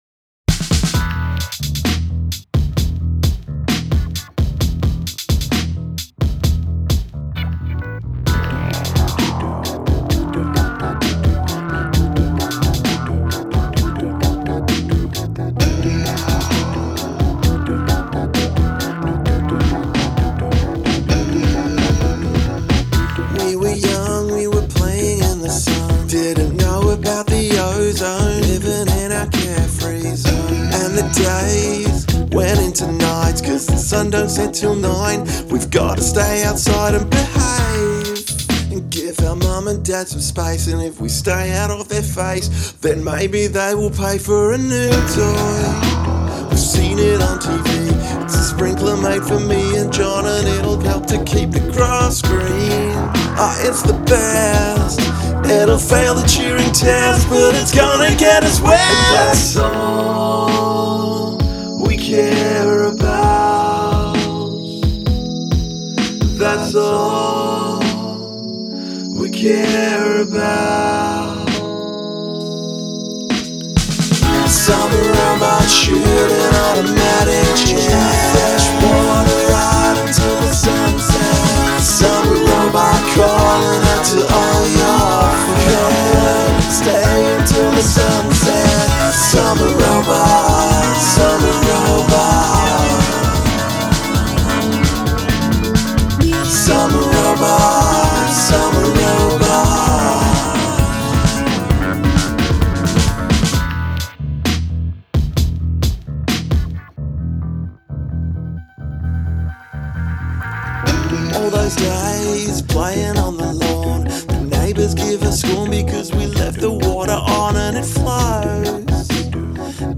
I’ve got it set to 131bpm, I promise!